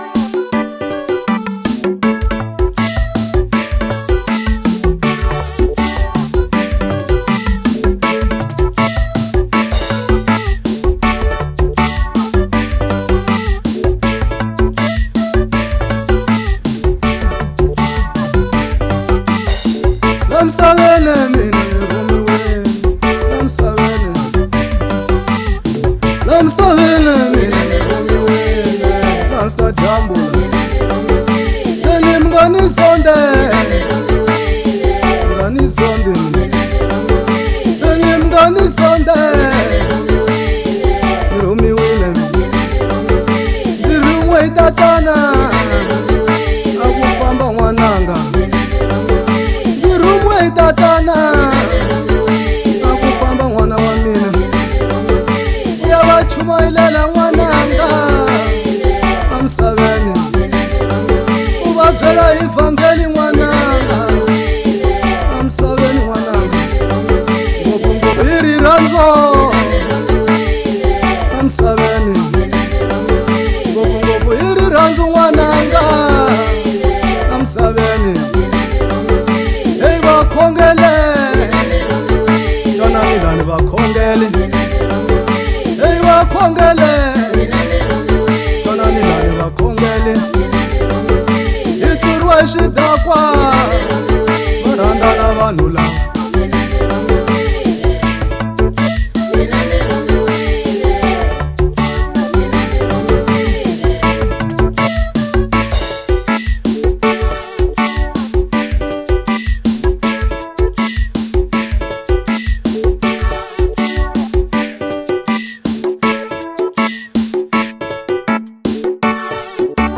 05:59 Genre : Gospel Size